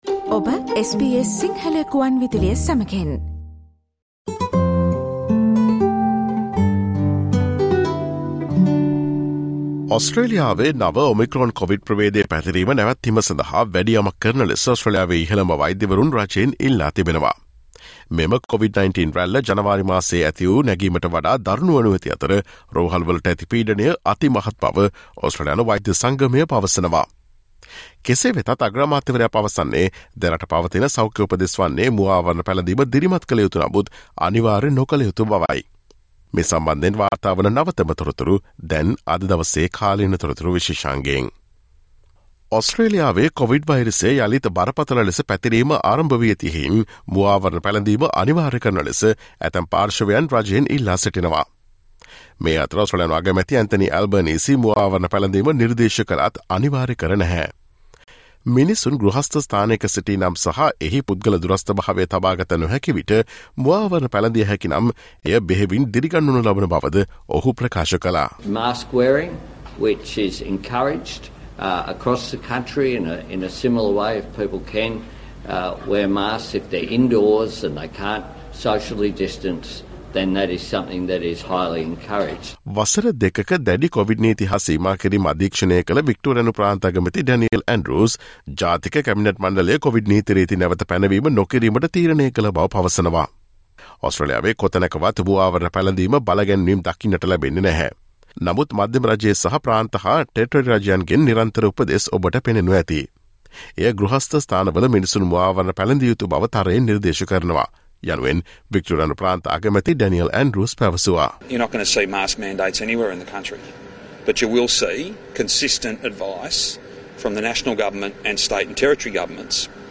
Listen to SBS Sinhala's current affairs feature, which aired on Thursday 21 July featuring the information on Australia's top doctors have called on the government to do more to stop the spread of the new Omicron-Covid variant.